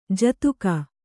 ♪ jatuka